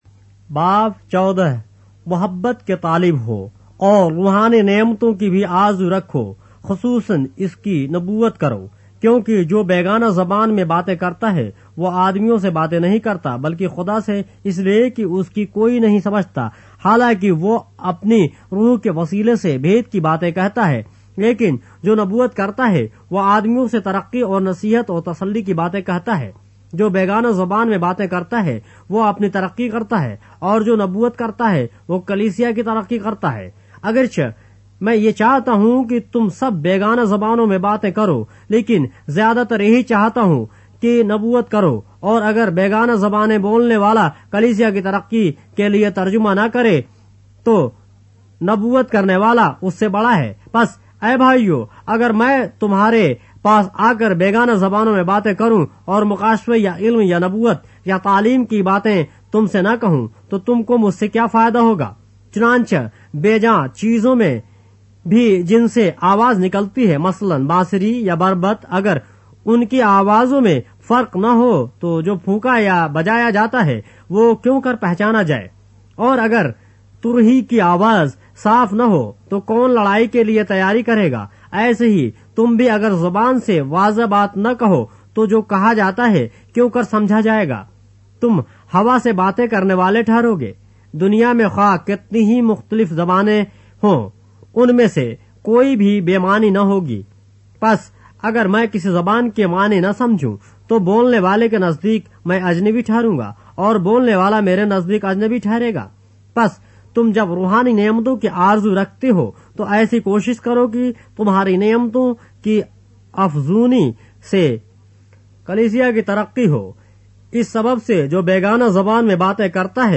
اردو بائبل کے باب - آڈیو روایت کے ساتھ - 1 Corinthians, chapter 14 of the Holy Bible in Urdu